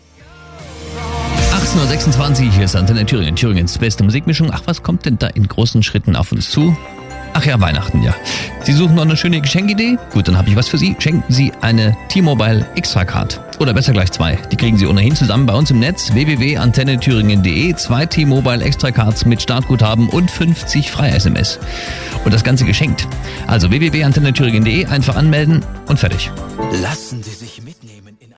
Livepromotion von Antenne Thüringen:
xtra-thueringen-promo.mp3